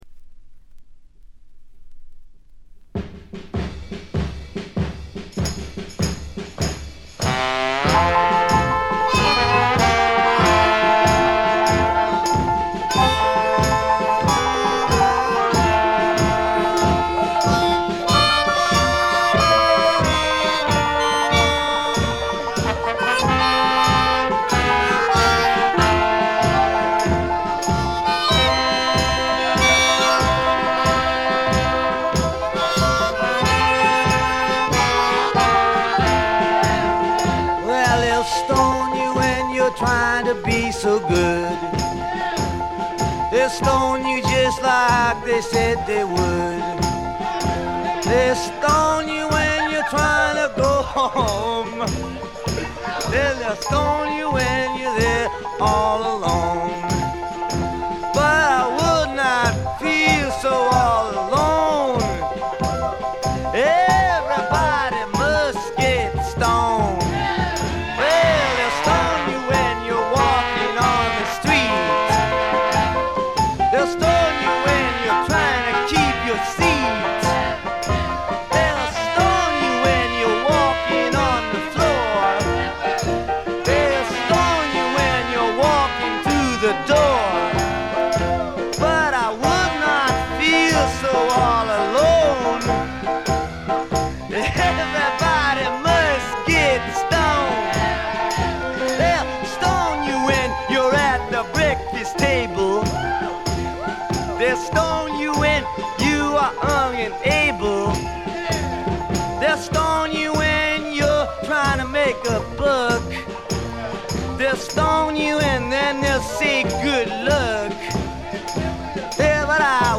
試聴曲は現品からの取り込み音源です。
vocals, guitar, harmonica, piano